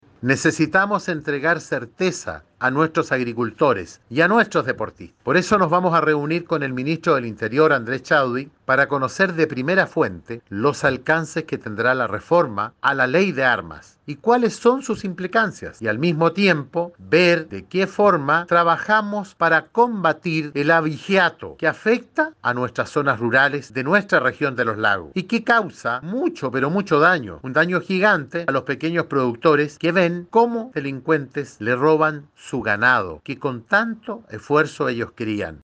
El Senador Iván Moreira explicó la necesidad de reunión con el ministro del Interior.